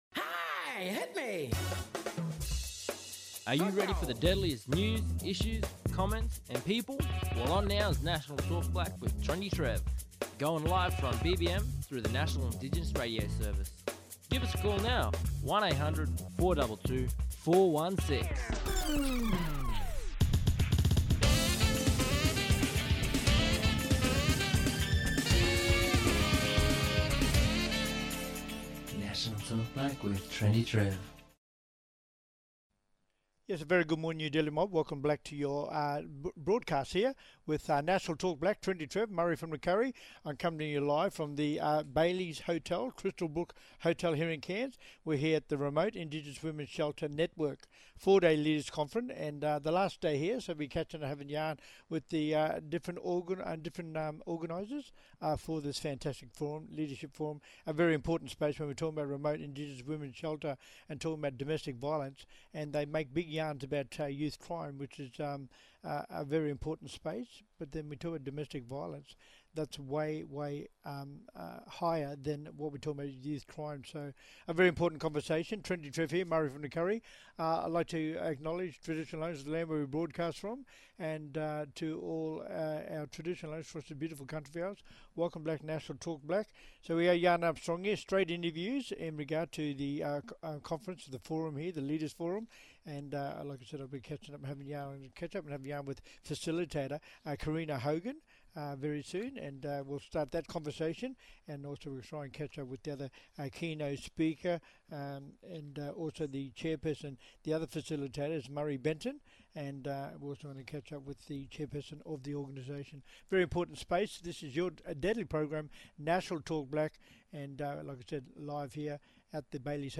Special Guests Live today from the Remote/Indigenous Women’s Shelter Network Forum. Where organisations and delegates are gathering To acknowledge the Historic Milestone as the Network Gathers After 20 years.